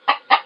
PixelPerfectionCE/assets/minecraft/sounds/mob/chicken/say1.ogg at e2b9fb81a32d08dc7460ed85389bec3c0541a9a7